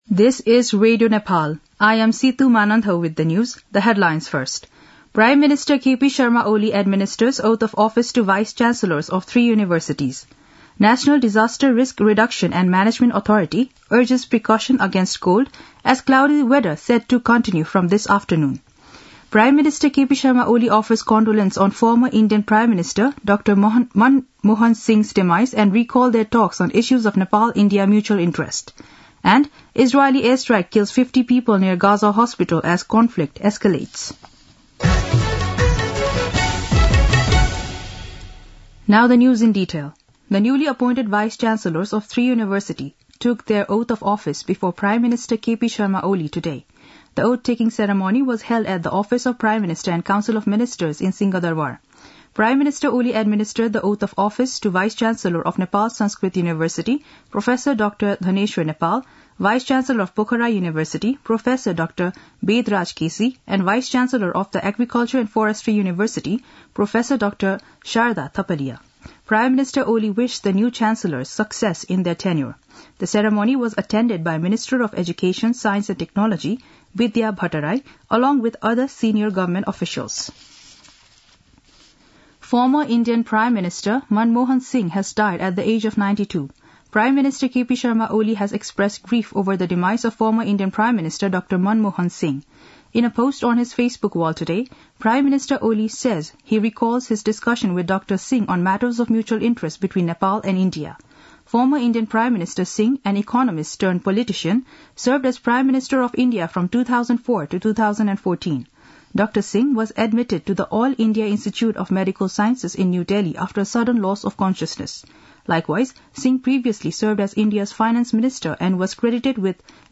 An online outlet of Nepal's national radio broadcaster
दिउँसो २ बजेको अङ्ग्रेजी समाचार : १३ पुष , २०८१
2-pm-english-news-1-17.mp3